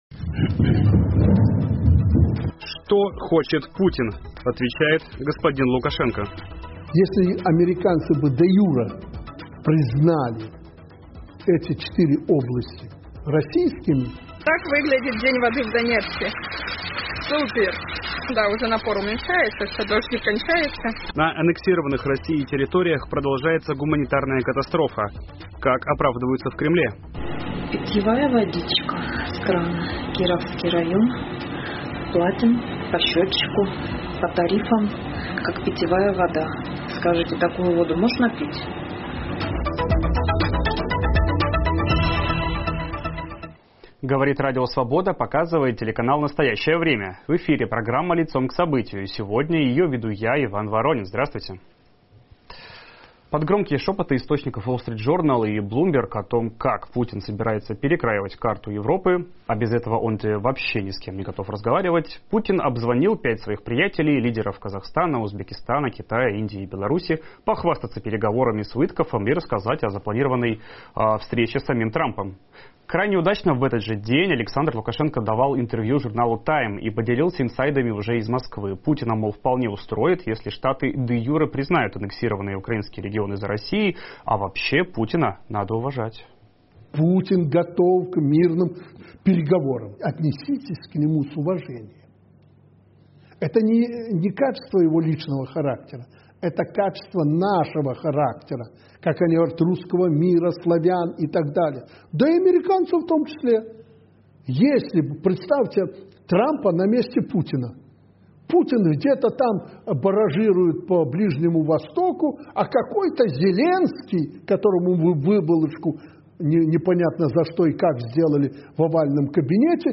Что Россия принесла на оккупированные территории и может ли Украина пойти на территориальные уступки? Обсуждаем в эфире программы "Лицом к событию" с журналистом